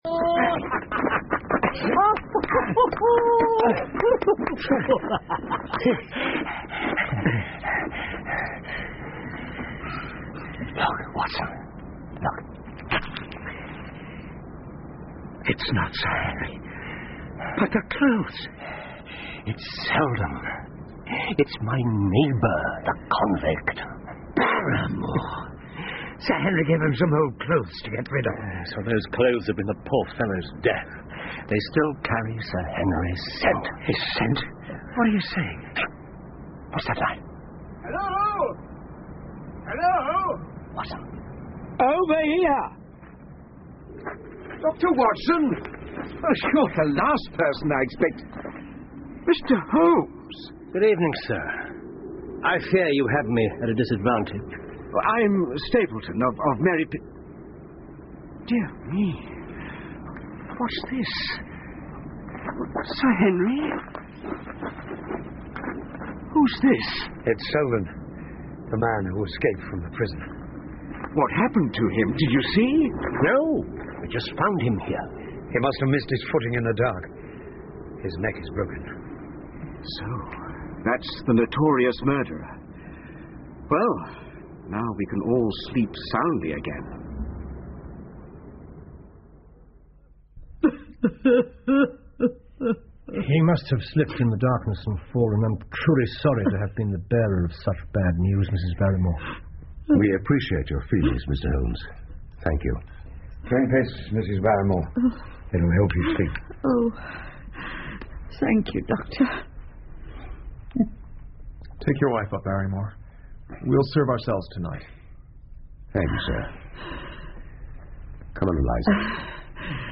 福尔摩斯广播剧 64 The Hound Of The Baskervilles - Part 02-8 听力文件下载—在线英语听力室
在线英语听力室福尔摩斯广播剧 64 The Hound Of The Baskervilles - Part 02-8的听力文件下载,英语有声读物,英文广播剧-在线英语听力室